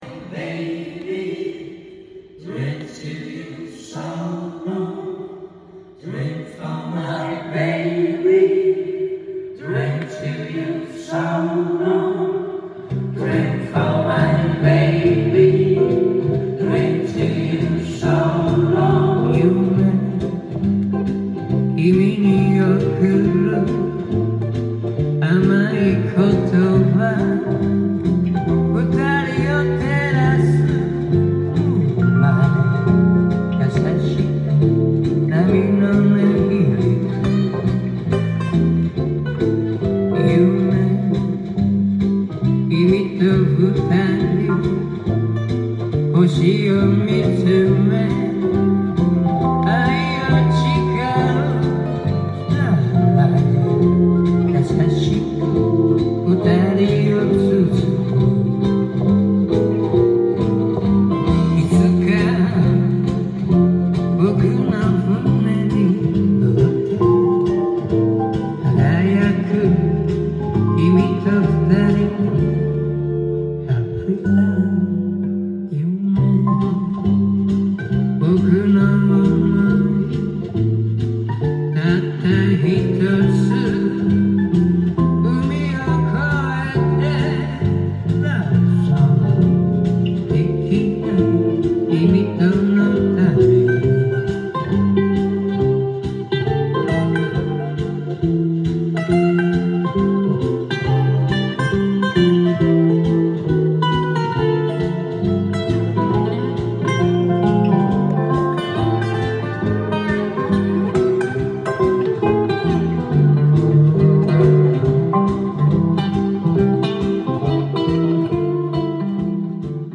ジャンル：J-ROCK
店頭で録音した音源の為、多少の外部音や音質の悪さはございますが、サンプルとしてご視聴ください。
音が稀にチリ・プツ出る程度